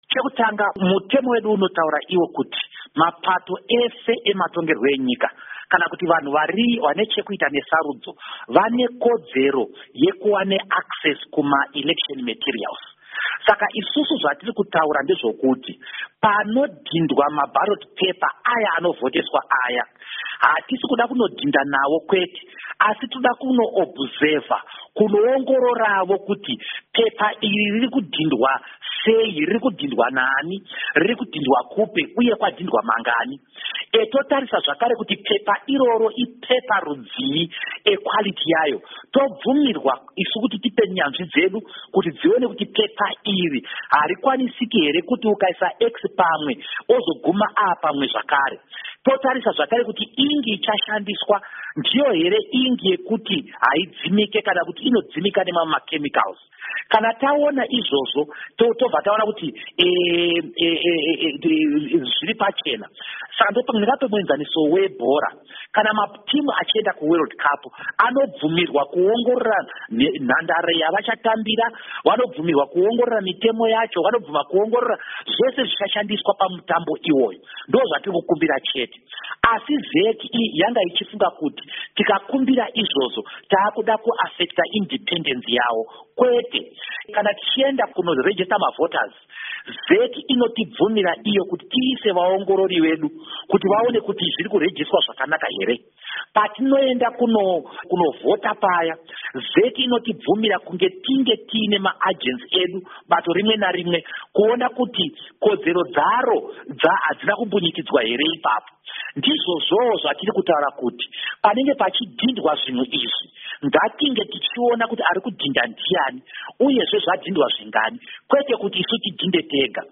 Hurukuro naVaDouglas Mwonzora naVaPaul Mangwana